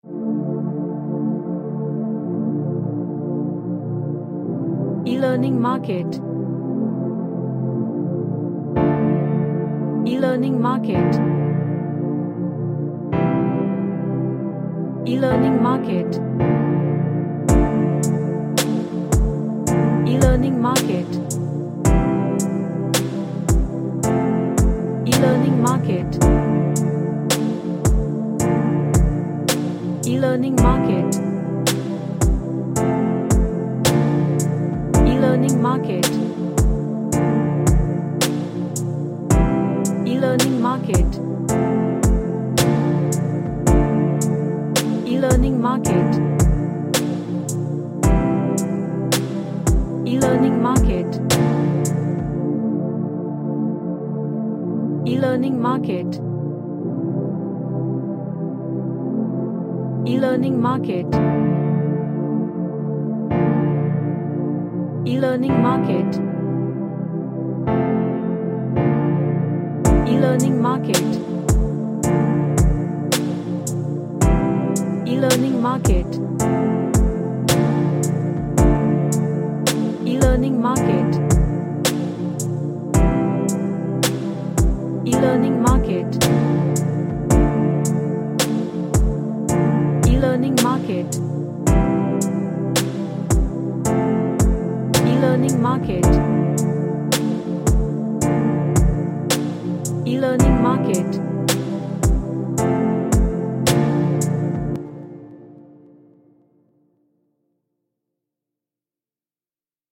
A Lo-Fi track with dark chords.
Sad / Nostalgic